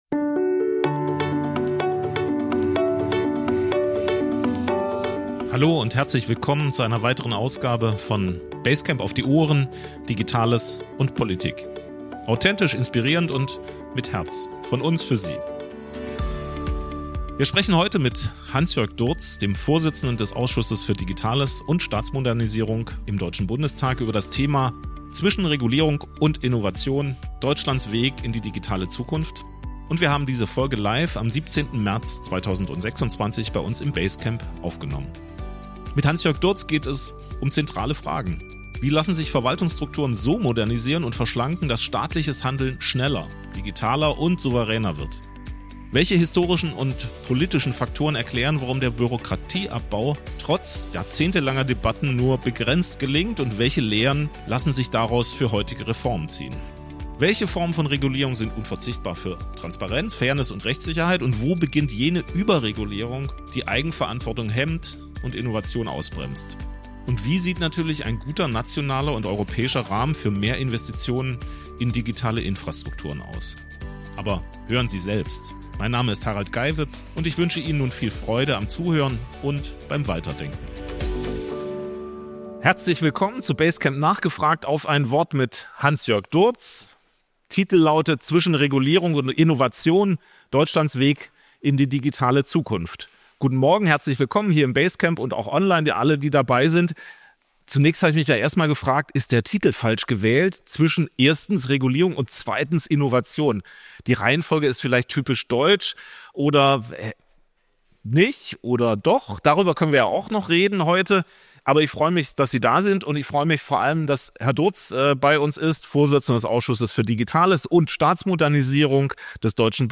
Beschreibung vor 2 Wochen Wir sprechen heute mit Hansjörg Durz, dem Vorsitzenden des Ausschusses für Digitales und Staatsmodernisierung. Ein Jahr nach der Bundestagswahl wollen wir herausfinden, was auf der Agenda des zweiten Jahres der Regierung Merz digitalpolitisch steht und darüber diskutieren, wie Deutschland im Spannungsfeld von Regulierung und Innovation handlungsfähiger werden kann.